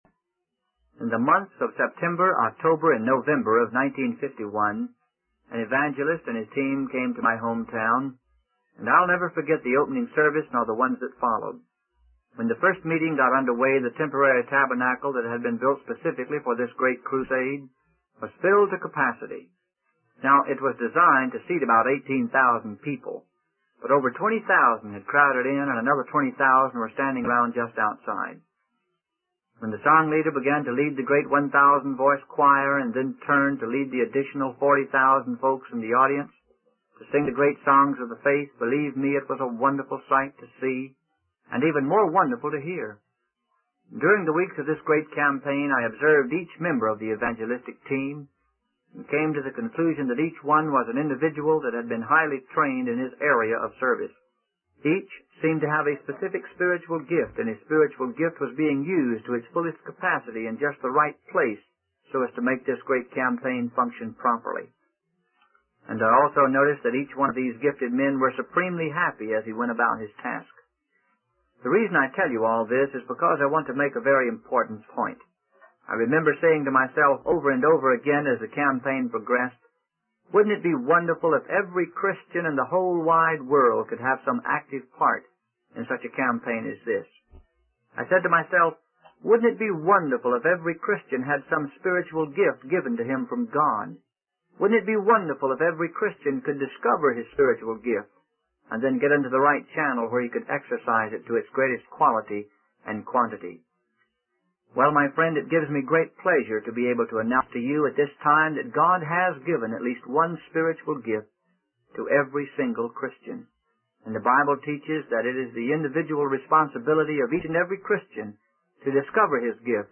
In this sermon, the speaker emphasizes the importance of thinking soberly and making a realistic assessment of our abilities. He uses the analogy of a soldier being equipped to fight tanks to illustrate the concept. The speaker encourages listeners to search the scriptures and discover their spiritual gifts, so they can serve in the best way possible.